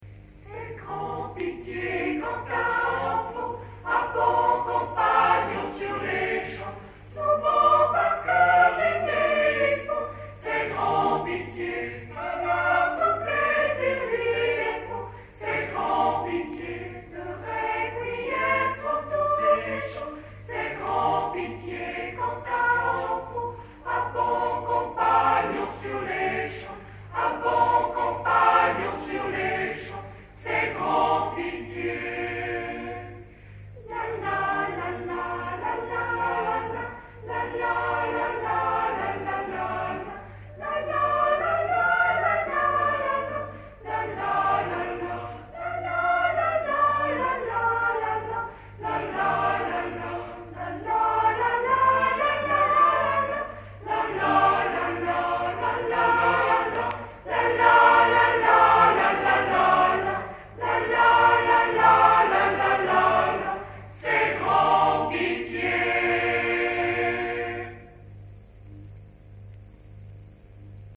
CHORALE RENAISSANCE de SAULXURES SUR MOSELOTTE